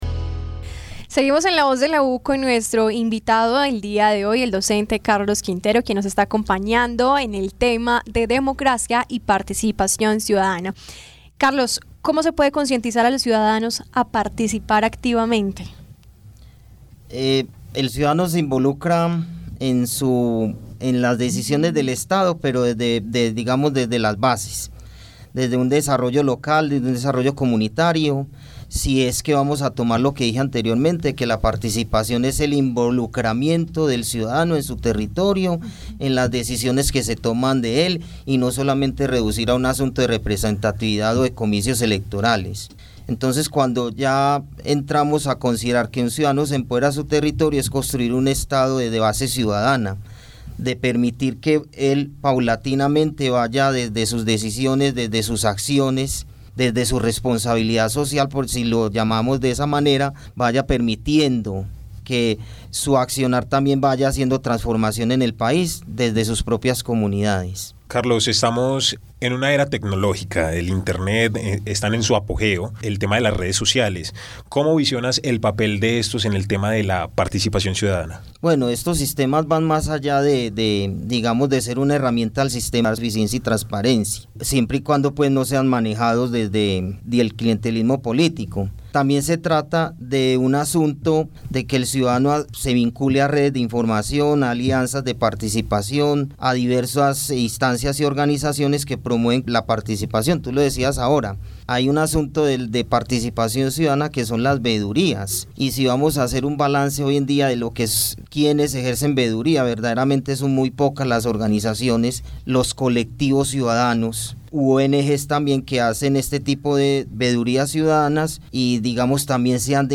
quien estuvo como invitado en el programa La Voz de la U.